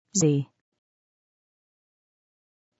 Aux Etats-Unis, le "z" se prononce pareil que "b", "c", "d" etc. c'est-à-dire avec un son [i:], ce qui donne:
Z américain.